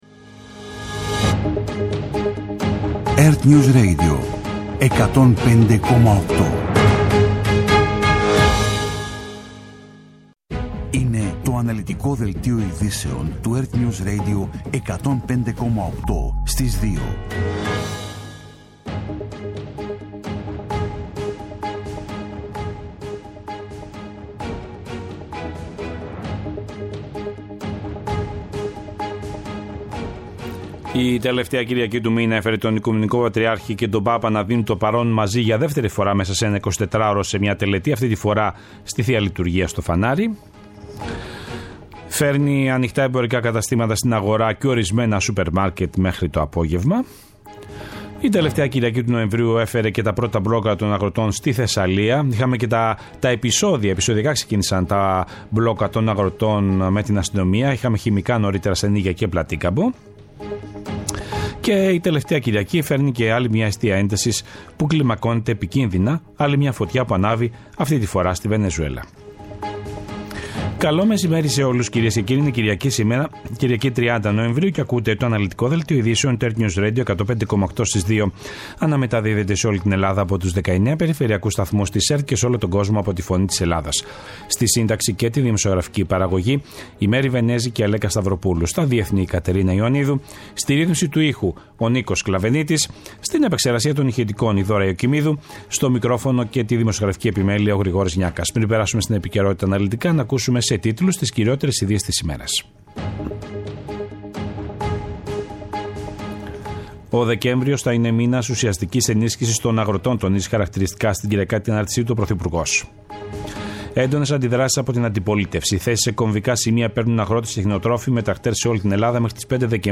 Το αναλυτικό ενημερωτικό μαγκαζίνο στις 14:00.
Με το μεγαλύτερο δίκτυο ανταποκριτών σε όλη τη χώρα, αναλυτικά ρεπορτάζ και συνεντεύξεις επικαιρότητας.